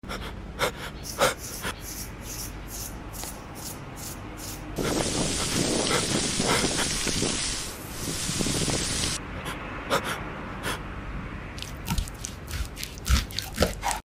Porky funny creepy weirdy asmr sound effects free download